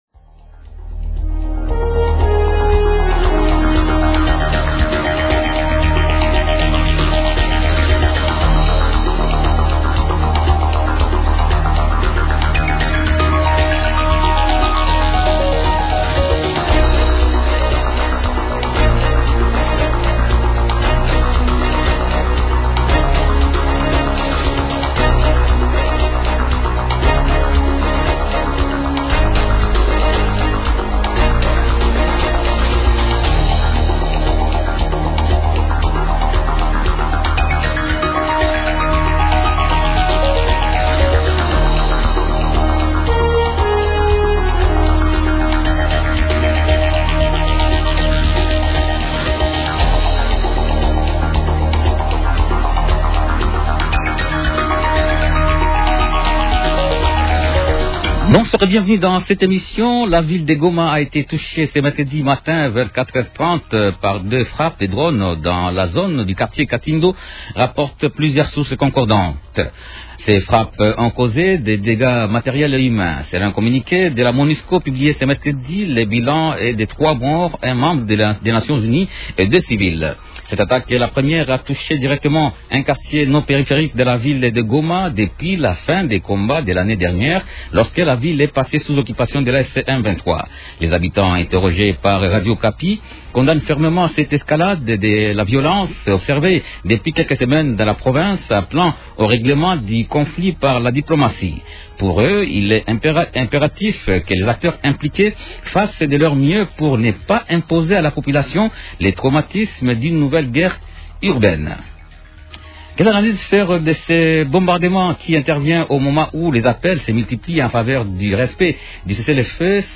-Et en ligne depuis la ville de Liège en Belgique